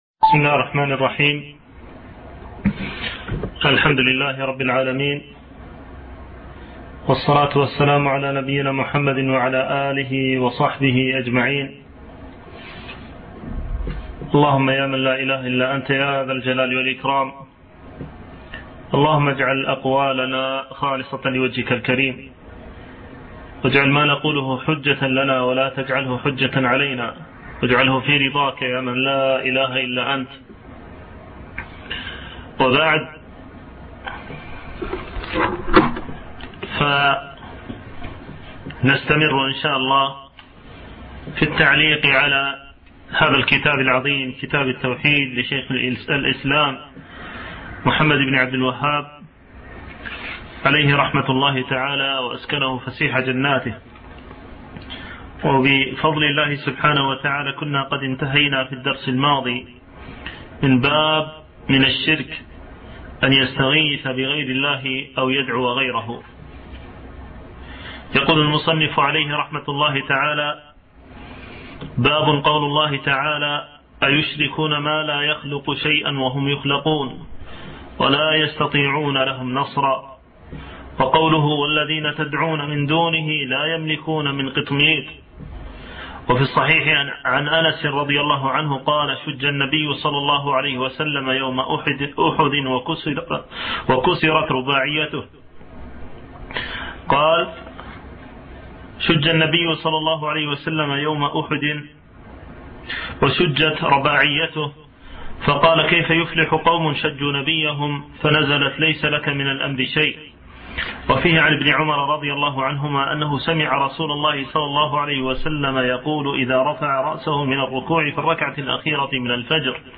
شرح كتاب التوحيد - الدرس الخامس عشر